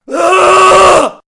陌生的男性叫声
描述：男尖叫。使用Rode NT2a麦克风录制。
声道立体声